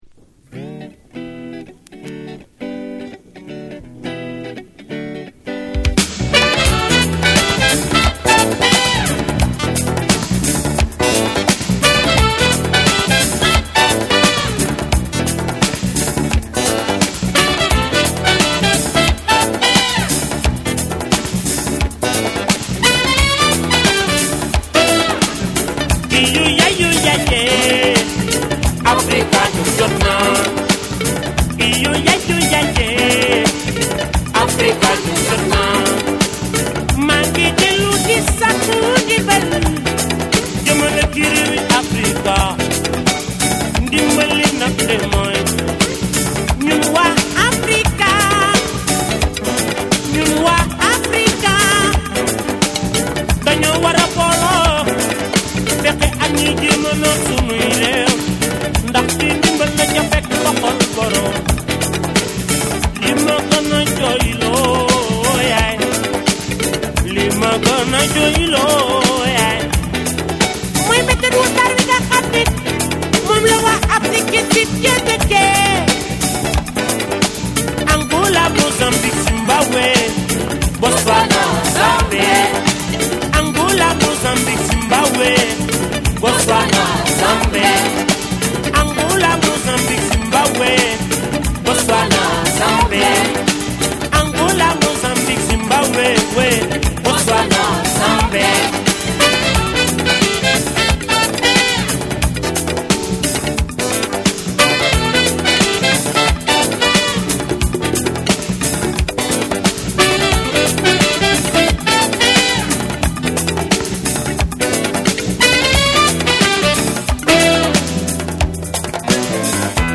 BREAKBEATS / ORGANIC GROOVE / WORLD